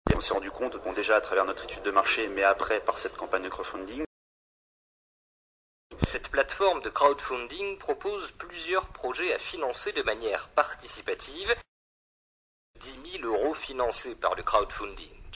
A écouter ici, pour la beauté de la prononciation française!